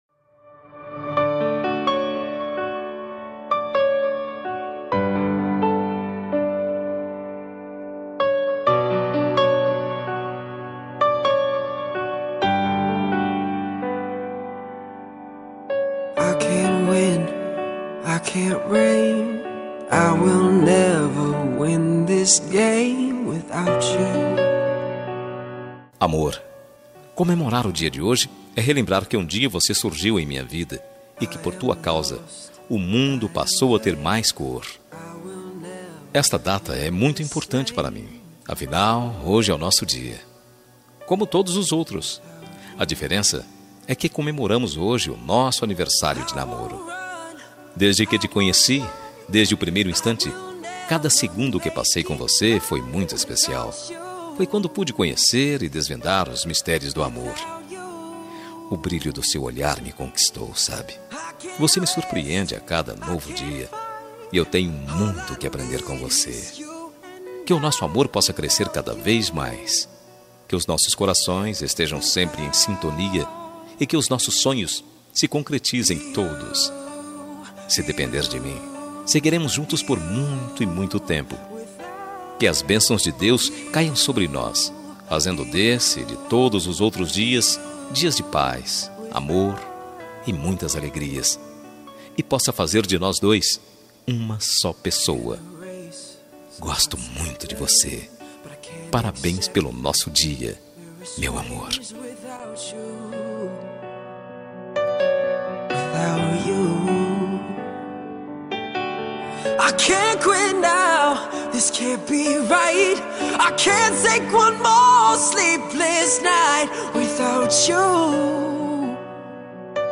Telemensagem Aniversário de Namoro – Voz Masculina – Cód: 8101- Linda.
8101-aniv-namoro-masc.m4a